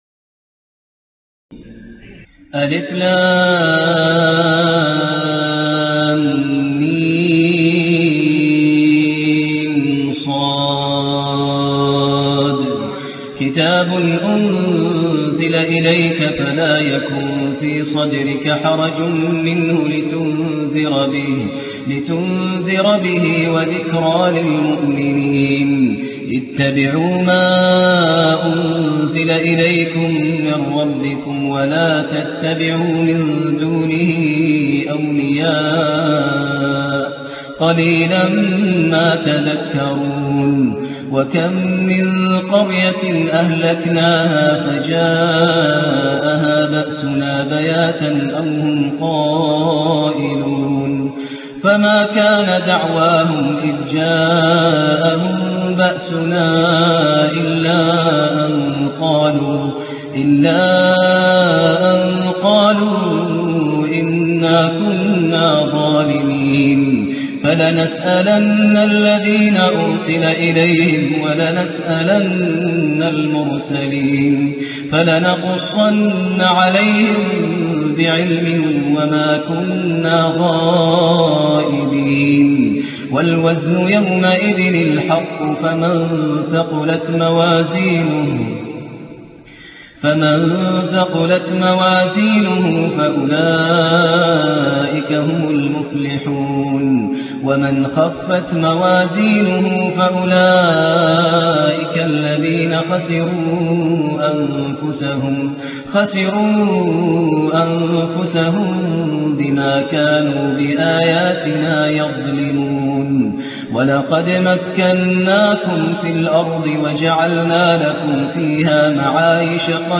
تهجد ليلة 27 رمضان 1427هـ من سورة الأعراف (1-93) Tahajjud 27 st night Ramadan 1427H from Surah Al-A’raf > تراويح الحرم النبوي عام 1427 🕌 > التراويح - تلاوات الحرمين